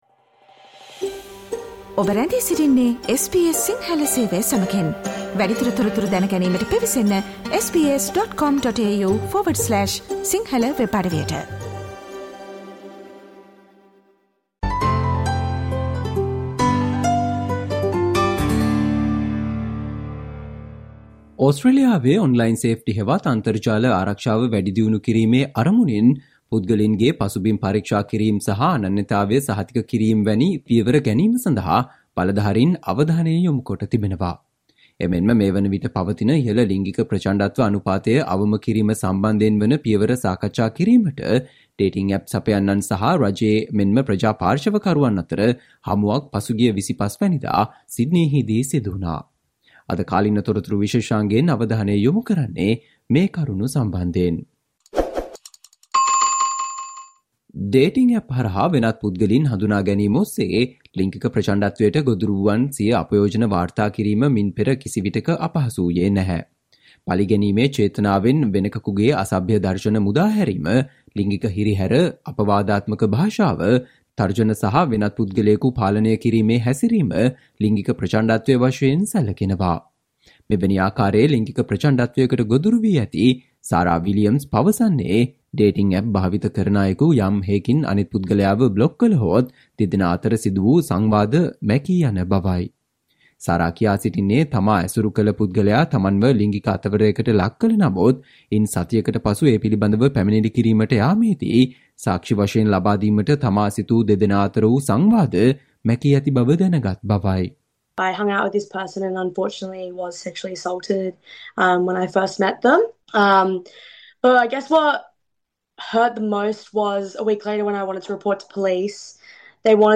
Today - 30 January, SBS Sinhala Radio current Affair Feature on National push for ways to protect dating app users from abuse